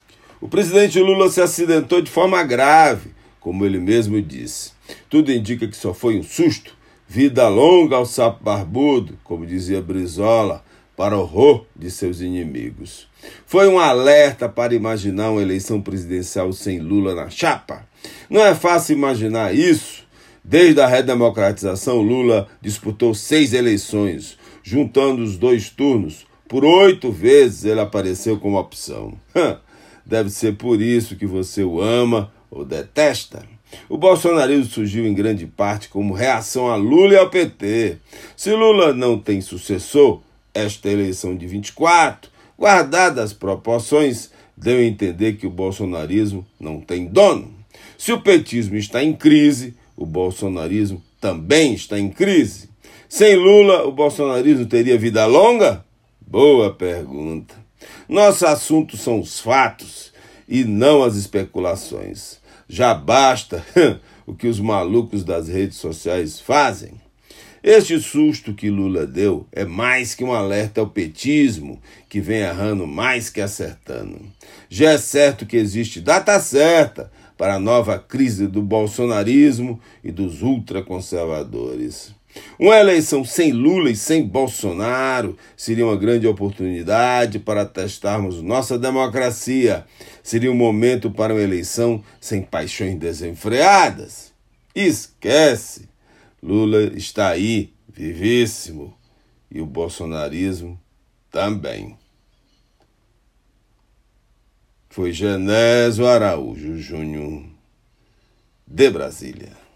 COMENTÁRIO DIRETO DE BRASIL